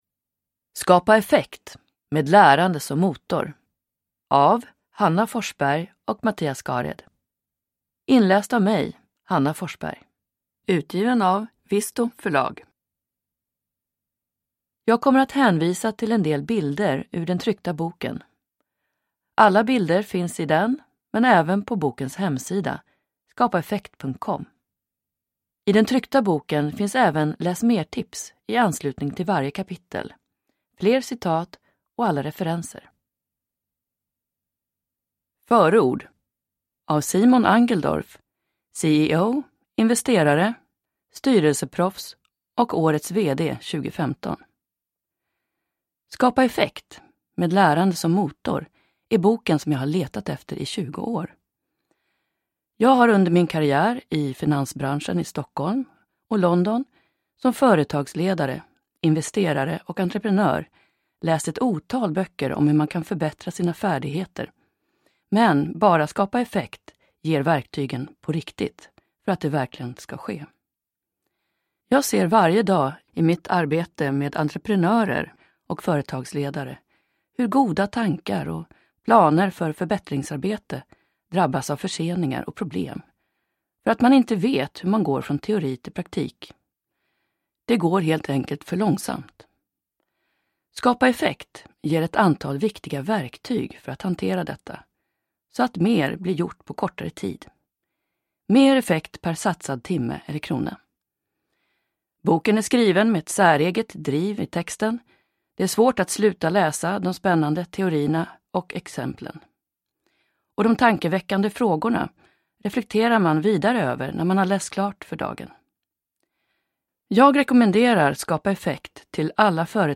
Skapa effekt : med lärande som motor – Ljudbok